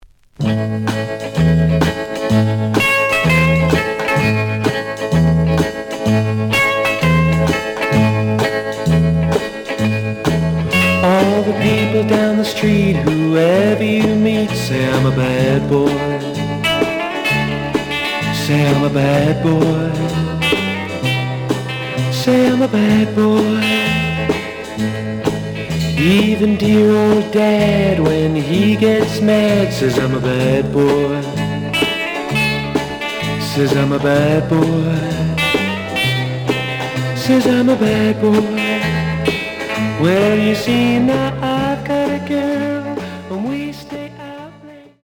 The audio sample is recorded from the actual item.
●Genre: Rhythm And Blues / Rock 'n' Roll
Slight sound cracking on A side.)